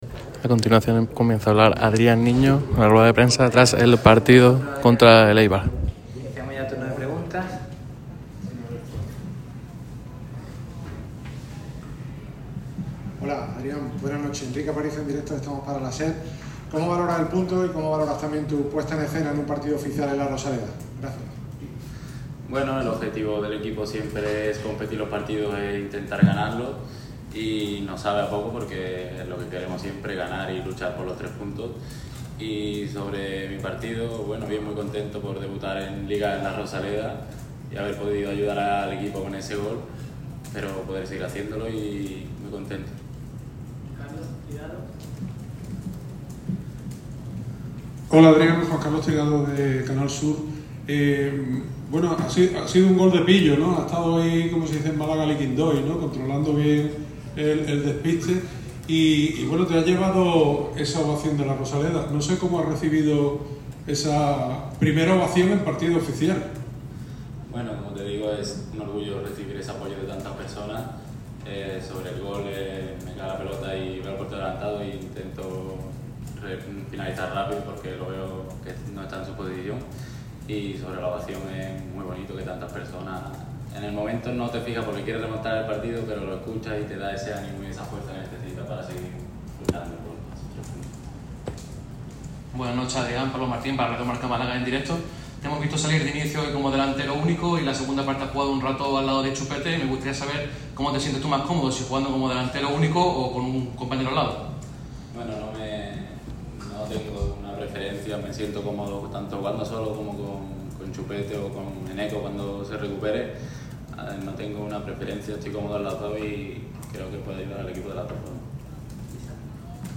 El delantero gaditano ha comparecido en la rueda de prensa tras su gran actuación contra el Eibar.